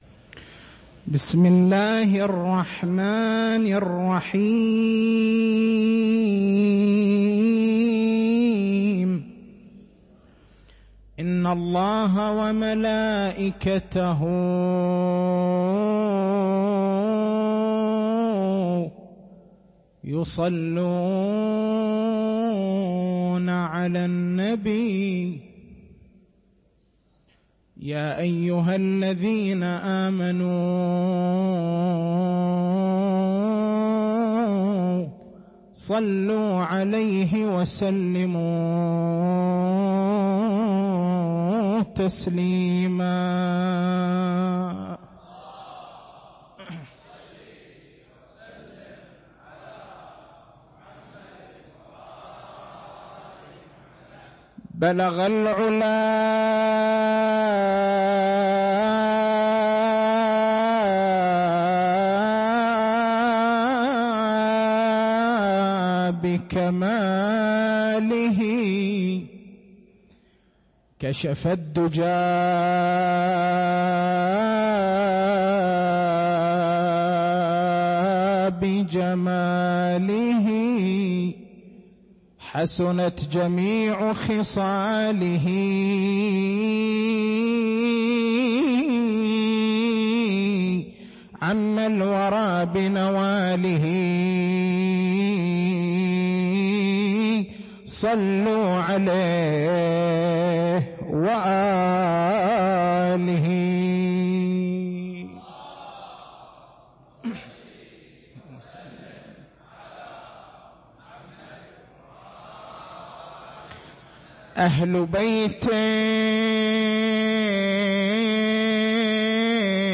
تاريخ المحاضرة: 02/07/1428 التسجيل الصوتي: شبكة الضياء > مكتبة المحاضرات > مناسبات متفرقة > أفراح آل محمّد